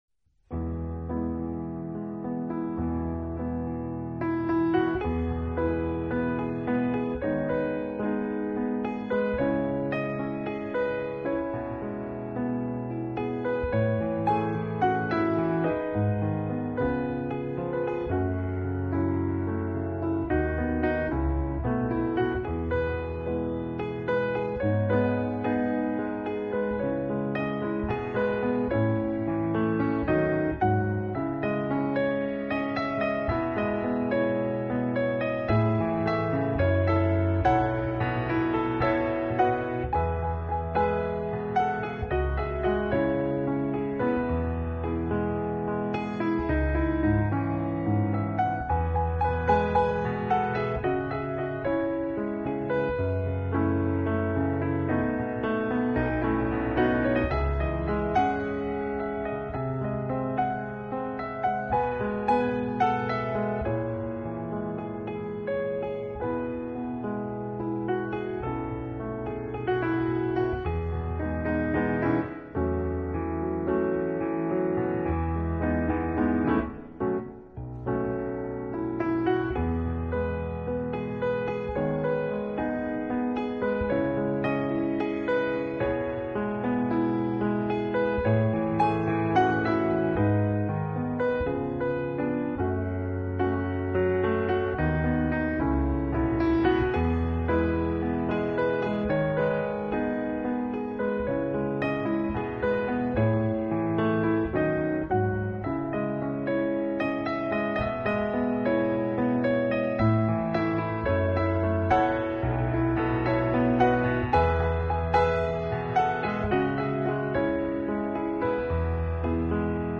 13首经典英文金曲，在极靚钢琴的演绎下，像春雨飘进聆听者